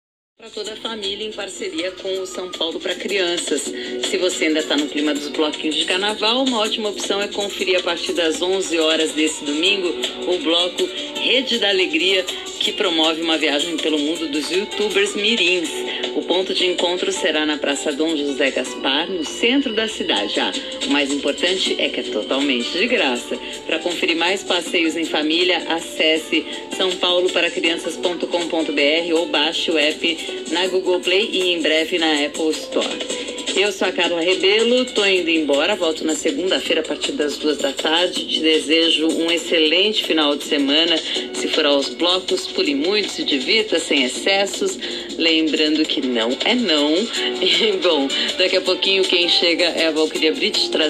Toda sexta-feira você pode acompanhar as melhores dicas de passeios em família no “Boletim São Paulo para Crianças”, na seção de Cultura da Alpha FM!